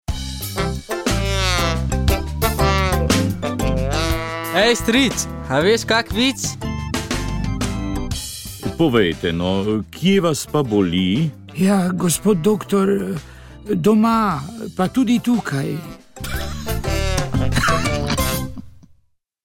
Pogovor ob dnevu vseh svetih